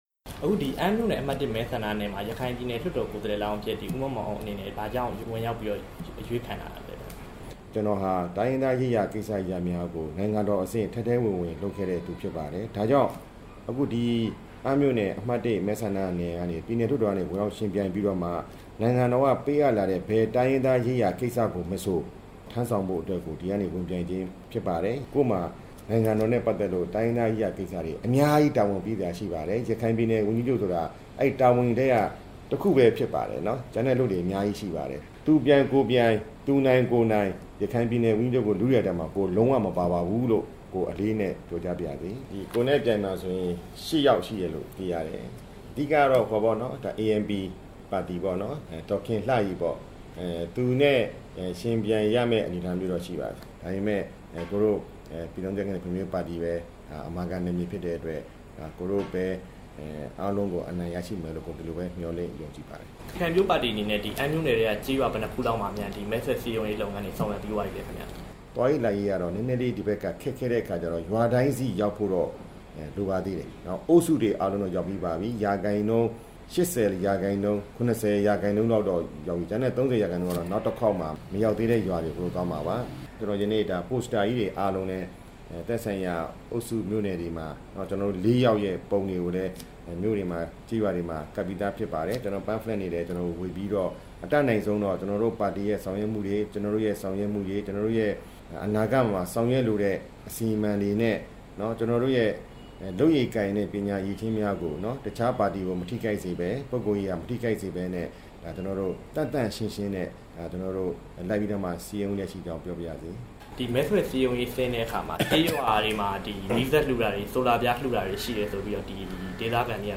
အမ်းမြို့နယ်က ဝင်ရောက်ယှဉ်ပြိုင်မယ့် ဦးမောင်မောင်အုန်းနဲ့ မေးမြန်းချက်